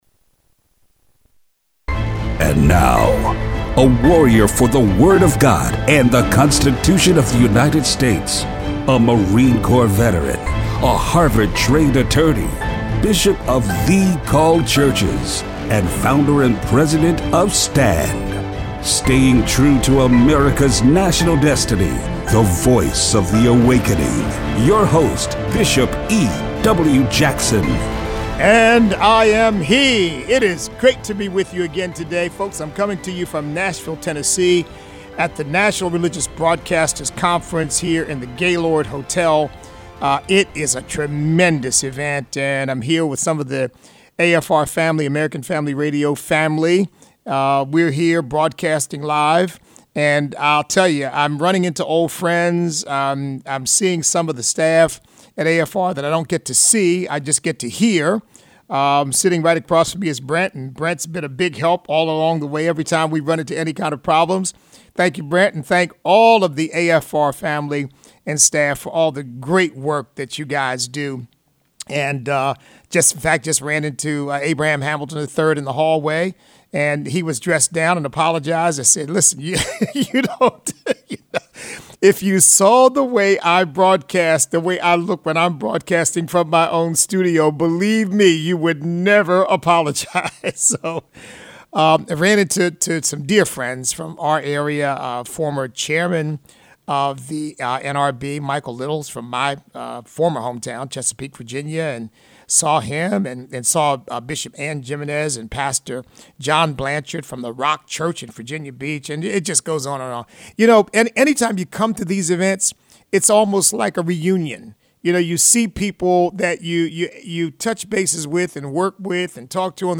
The Awakening: Live From The Religious Broadcasters Convention
Show Notes The Democratic debate. Socialism and Communism are the same thing. Listener call-in.